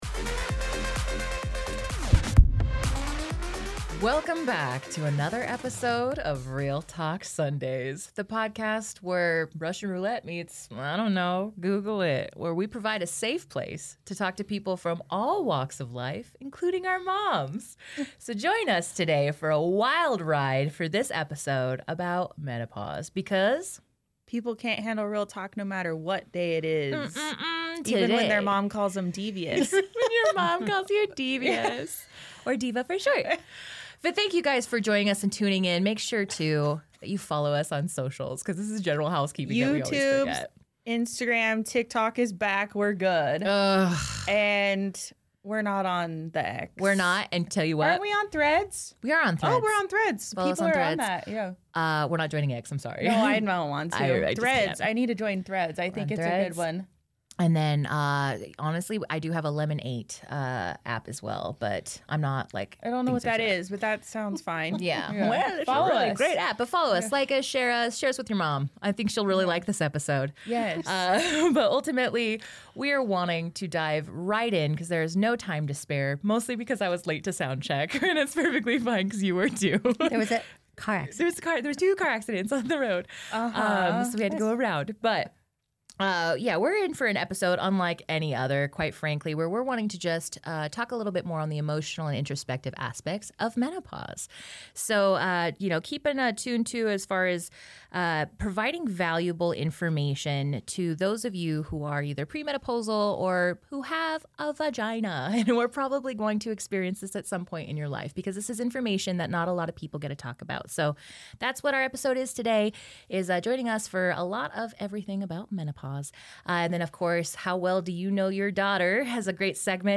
The conversation is both heartfelt and humorous, touching on the importance of community, self-care, and open dialogue.